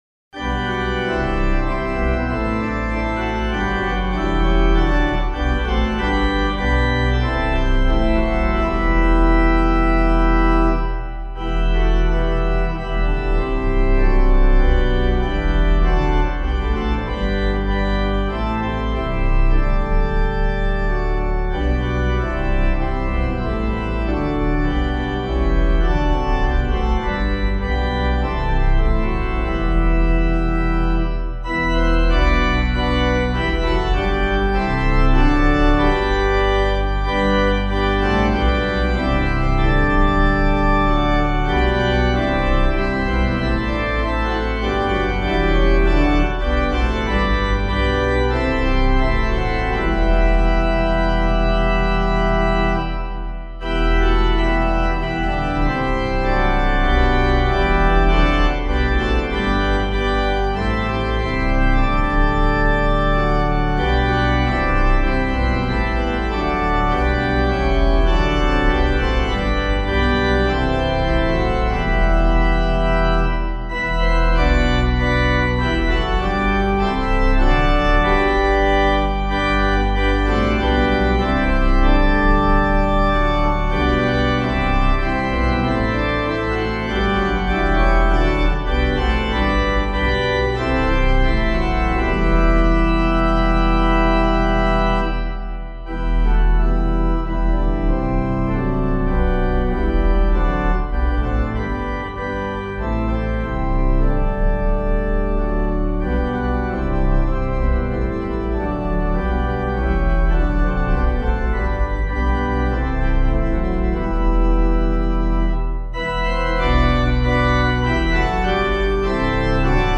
Hymn suitable for Catholic liturgy.
Composer: traditional English folk tune. Comments: The arrangement here is generic; the far more popular arrangement by Ralph Vaughan Williams is copyrighted (till 2029), but can be found in most hymnals.